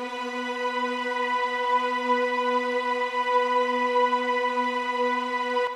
piano-sounds-dev
b6.wav